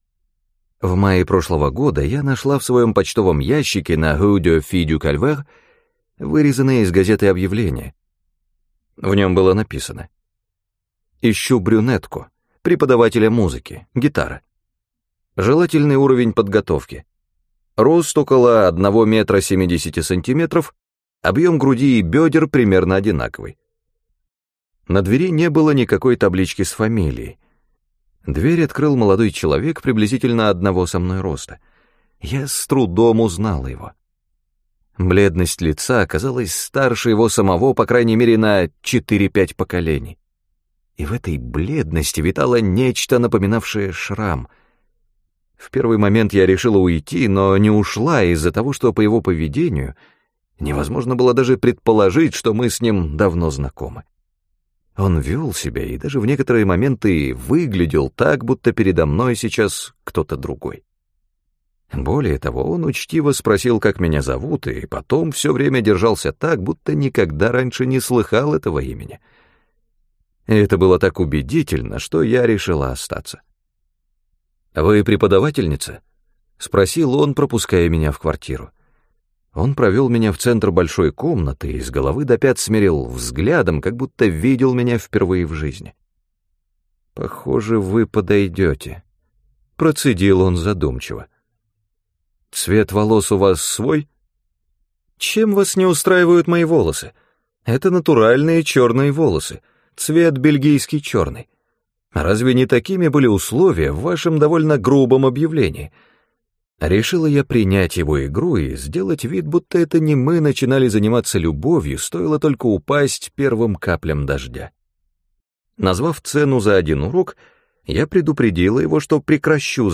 Аудиокнига Ящик для письменных принадлежностей | Библиотека аудиокниг